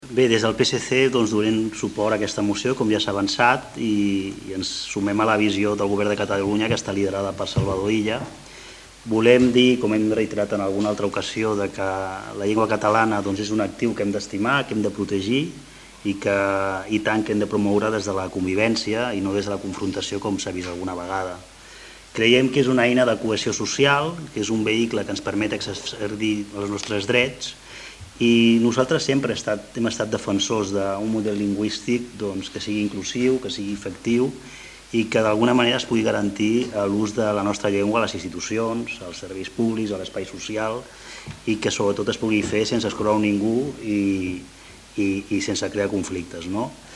Javier González, regidor de Noves Tecnologies i portaveu del PSC